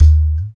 20 TALK DRUM.wav